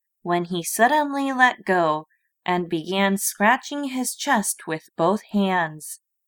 英語の朗読ファイル